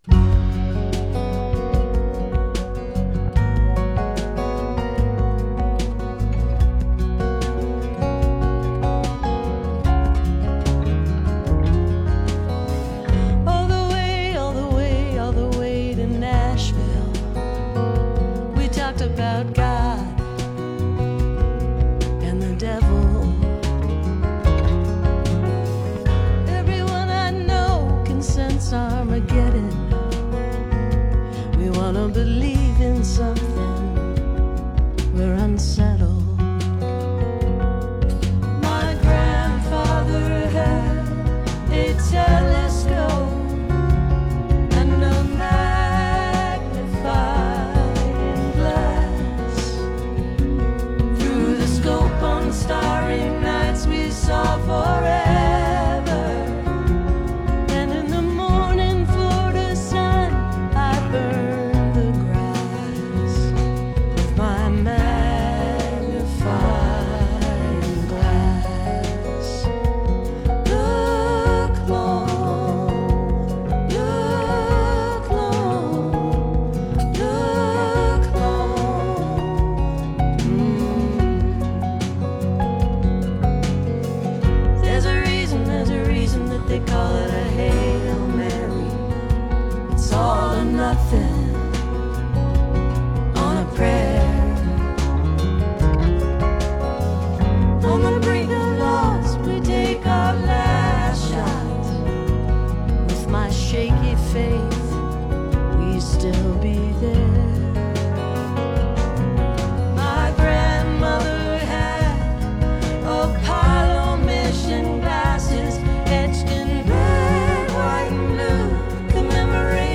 (captured from the web broadcast)